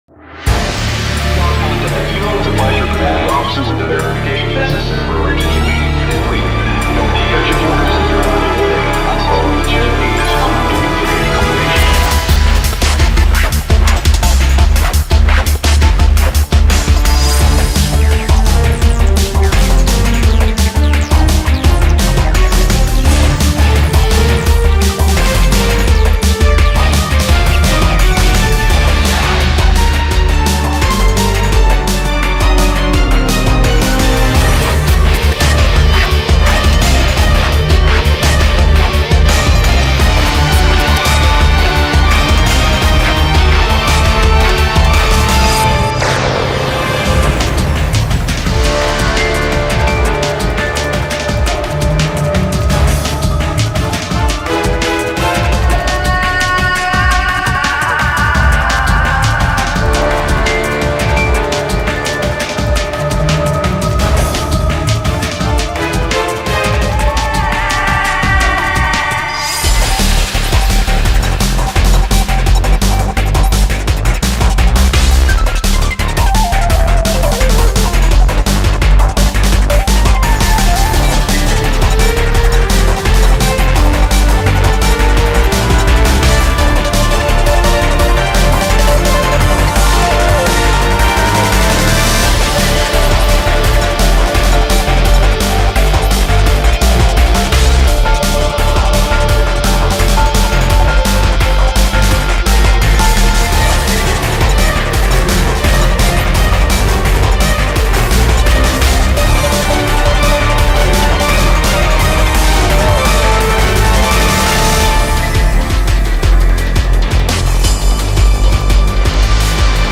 BPM85-170
Audio QualityPerfect (High Quality)
Comentarios[DRUM'N'BASS]